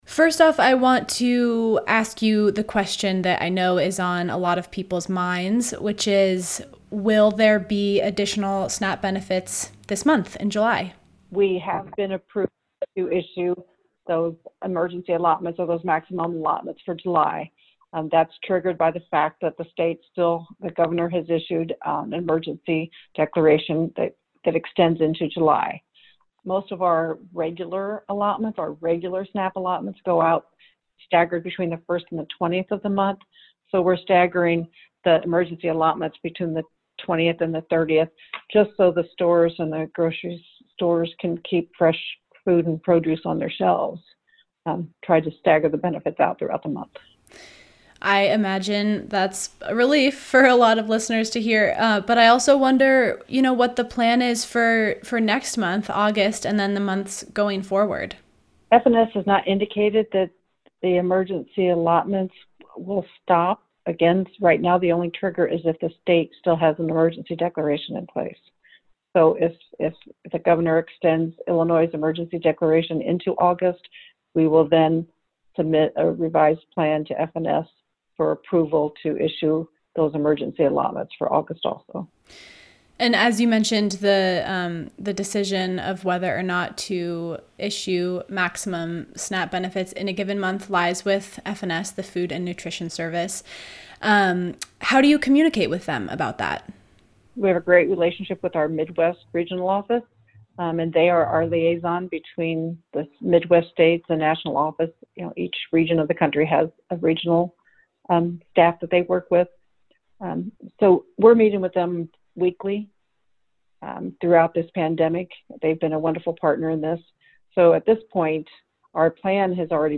This interview has been lightly edited for clarity.